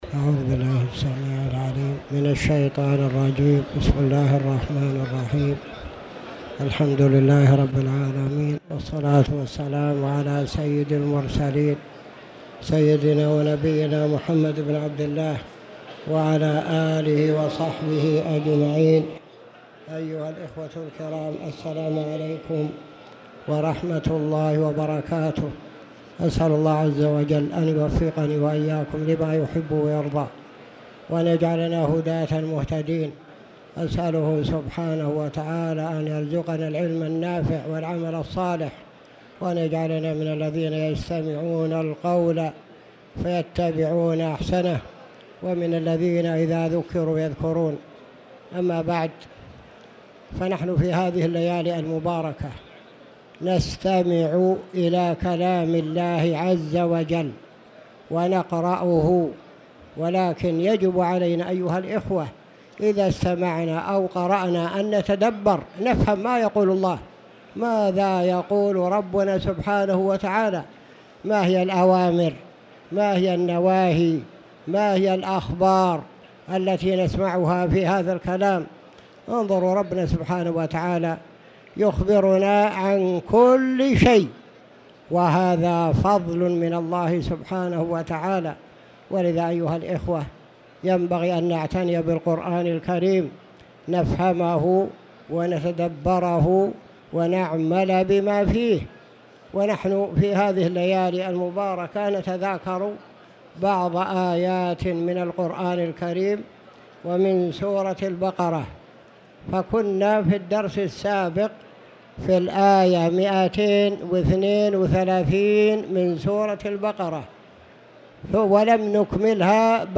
تاريخ النشر ٢٧ رمضان ١٤٣٧ هـ المكان: المسجد الحرام الشيخ